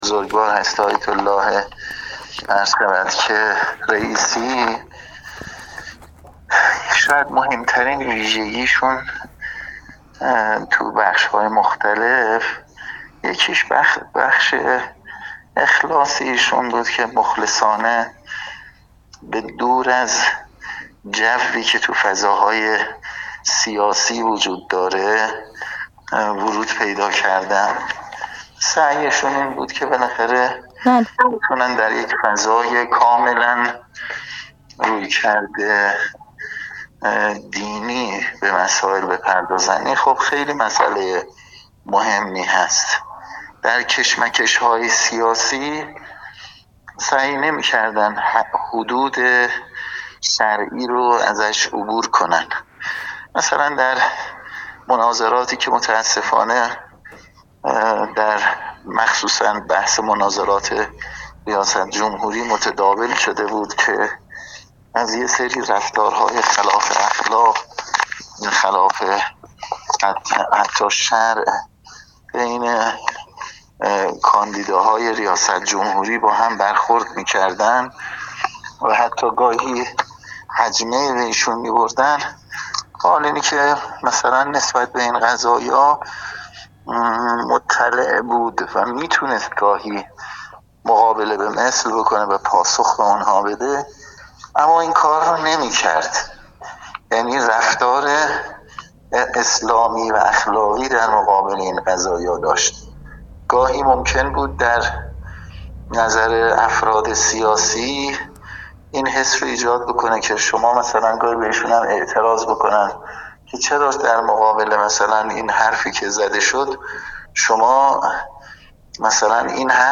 حجت الاسلام والمسلمین نصرالله پژمانفر، رئیس فراکسیون قرآن و عترت مجلس شورای اسلامی
حجت الاسلام والمسلمین نصرالله پژمانفر، رئیس فراکسیون قرآن و عترت مجلس شورای اسلامی در گفت‌وگو با ایکنا درباره ابعاد شخصیتی و مدیریتی شهید خدمت آیت‌الله سید ابراهیم رئیسی گفت: مهمترین ویژگی این شهید بزرگوار در بخش‌های مختلف اخلاص ایشان بود.